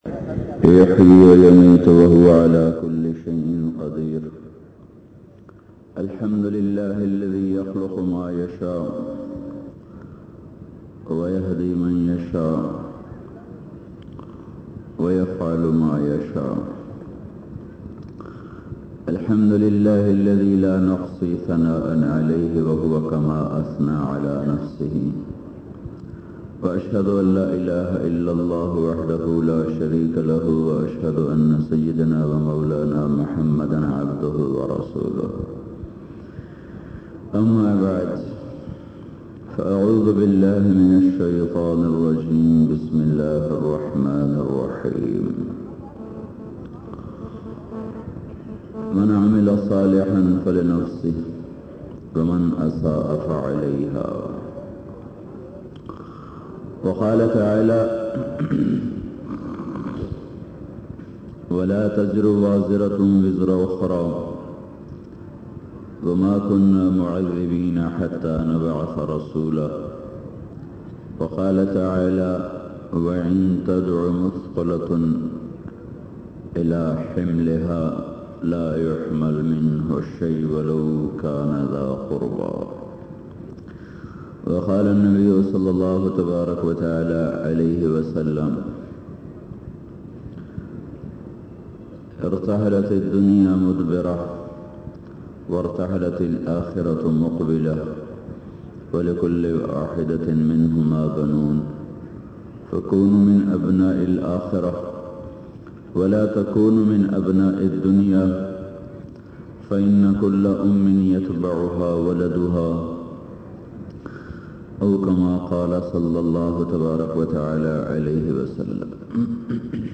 Eqeeda Toheed bayan mp3